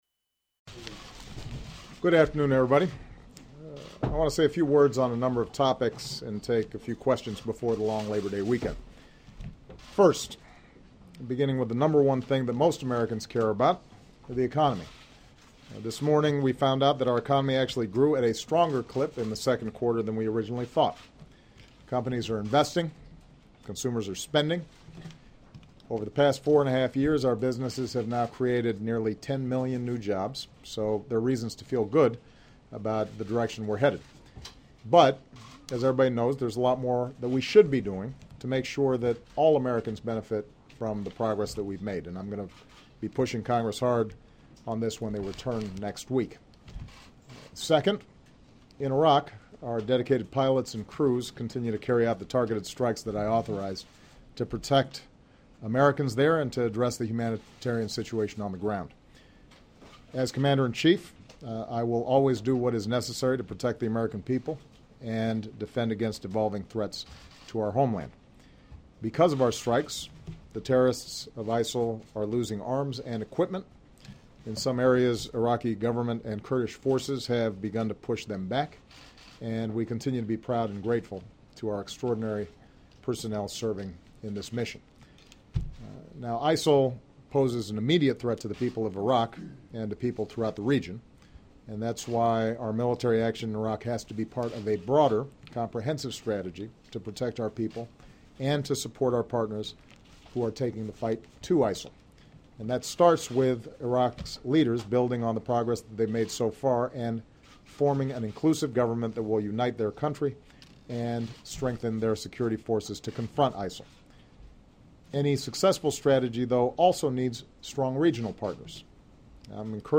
U.S. President Barack Obama provides an update on the strength of U.S. economy as well as America's position on the situations in Syria, Iraq and Ukraine then responds to reporters' questions